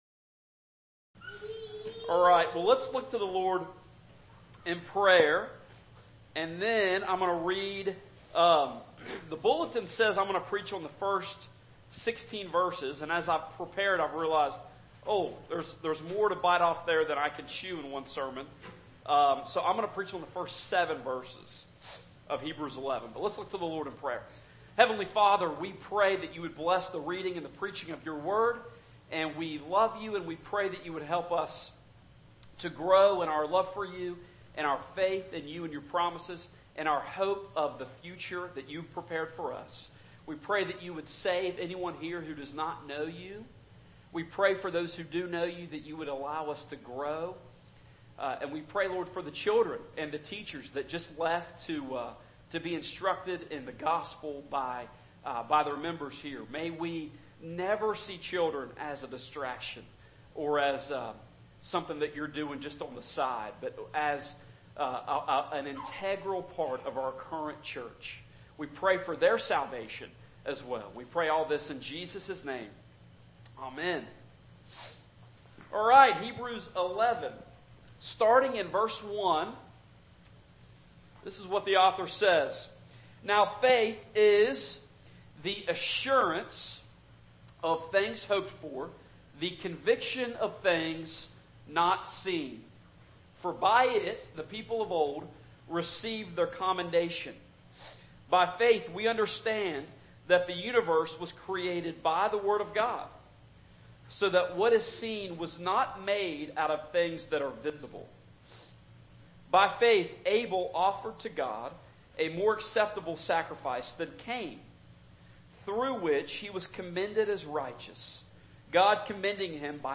Passage: Hebrews 11:1-7 Service Type: Morning Service